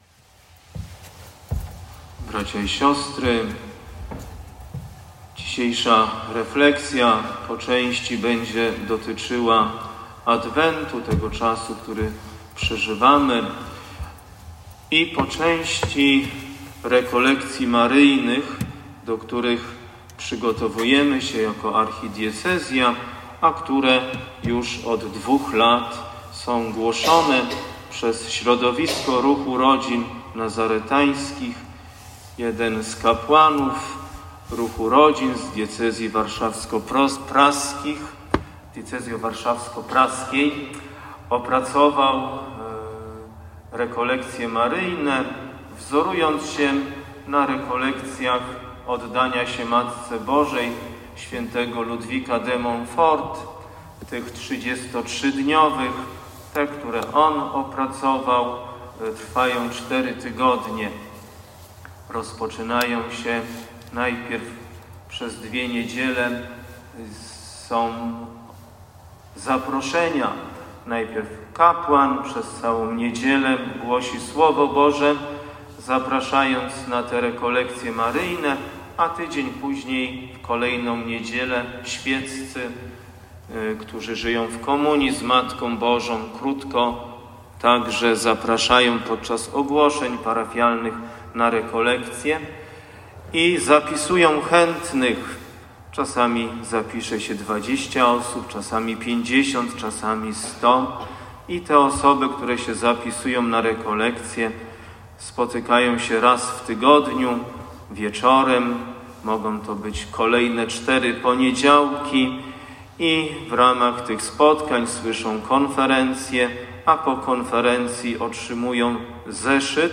Dzień skupienia w Pręgowie